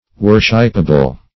Search Result for " worshipable" : The Collaborative International Dictionary of English v.0.48: Worshipable \Wor"ship*a*ble\, a. Capable of being worshiped; worthy of worship.